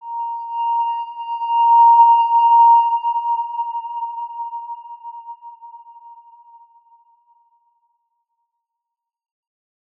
X_Windwistle-A#4-ff.wav